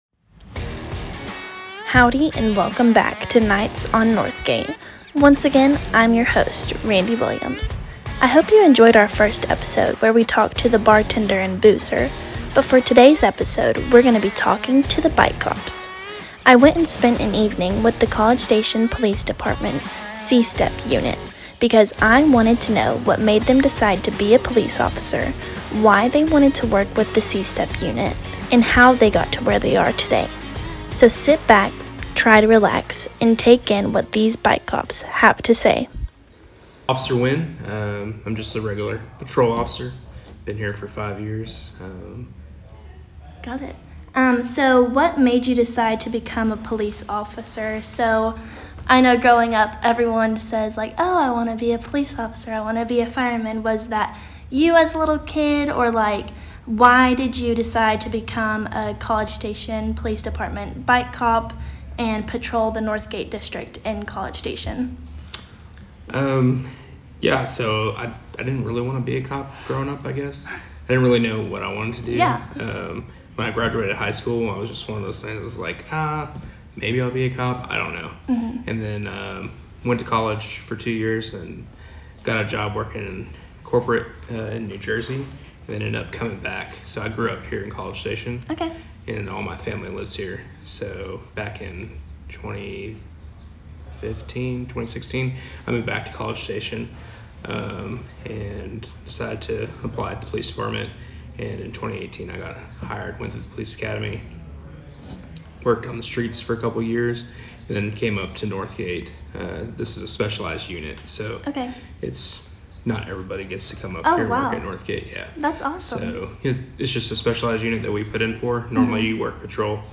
Listen to the College Station Police Department "bike cops" explain the Do's, Don'ts, and how to have safe "Nights On Northgate."
As you heard on Episode 1, here at Nights On Northgate, we interview bike cops, bouncers, bartenders, and boozers on how to make your Nights On Northgate as fun and safe as possible.